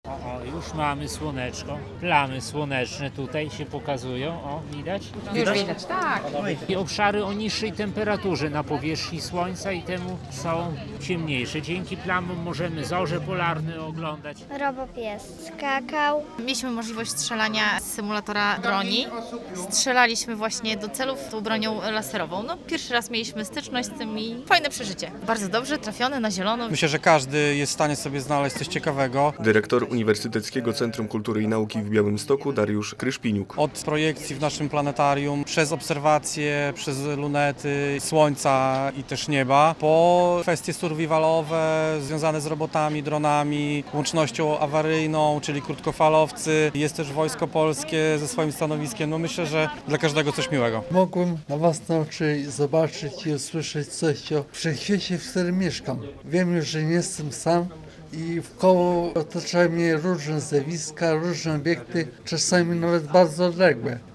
Kosmiczny Kampus UwB - relacja